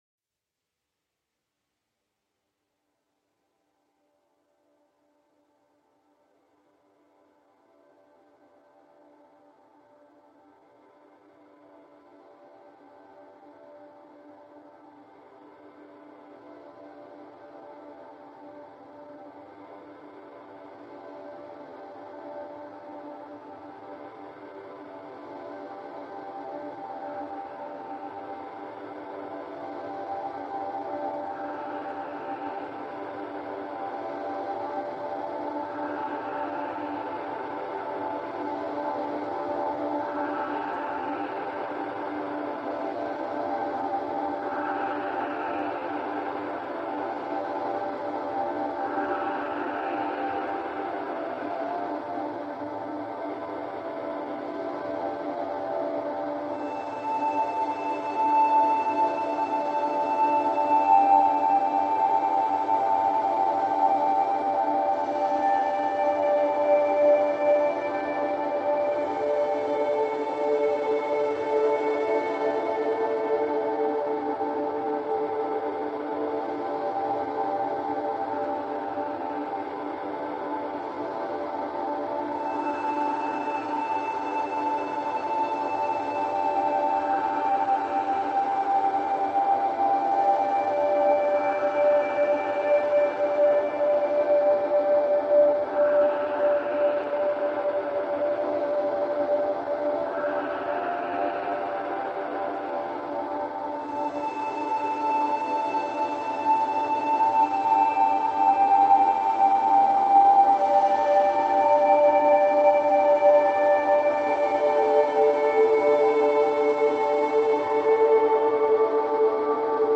ژانر: چاکرا